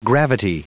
Prononciation du mot gravity en anglais (fichier audio)
Prononciation du mot : gravity